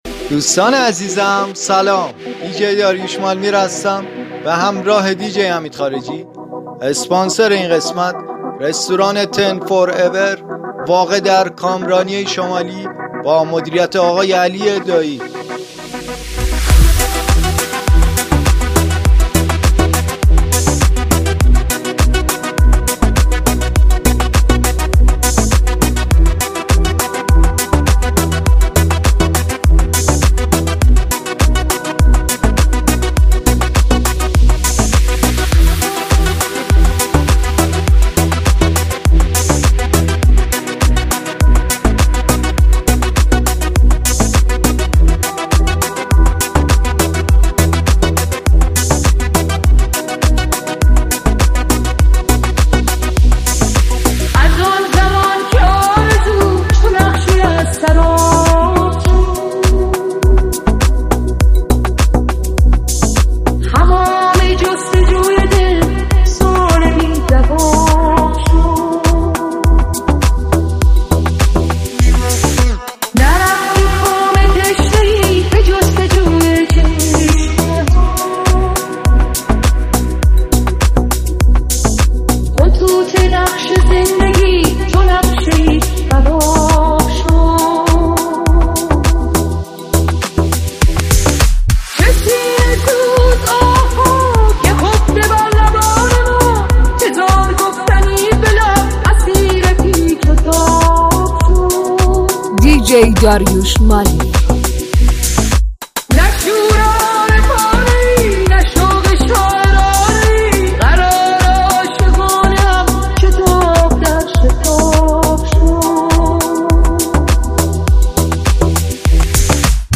با میکس خفن و ریتمیک
ریمیکس